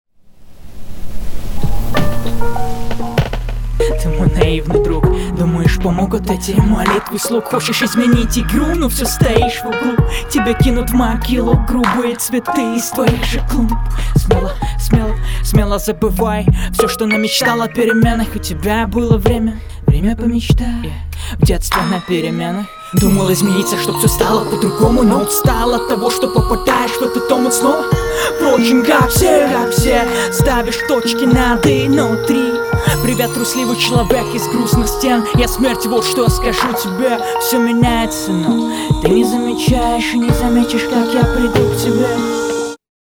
Шипящие бы как-то подлатать, а то сильно слух режет, но вообще интересно эмоционально раскрыл бит, и исполнение и текст понравились, звучит гармонично и атмосферно.